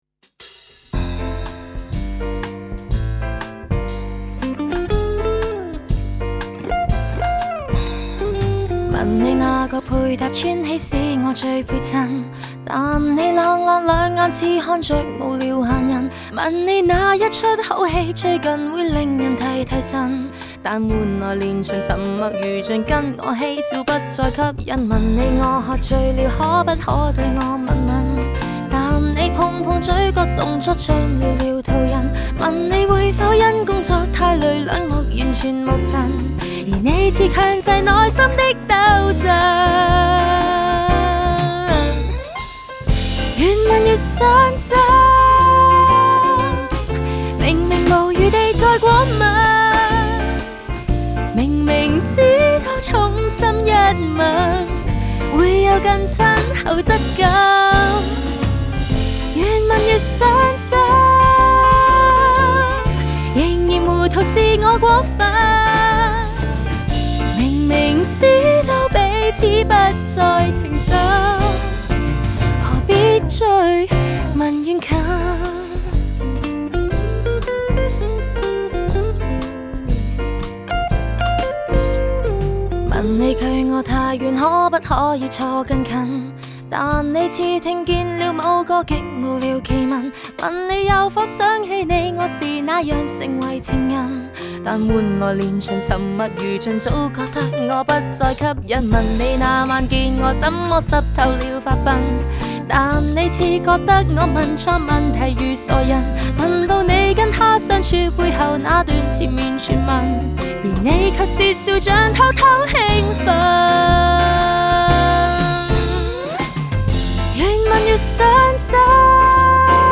Жанр: Cantopop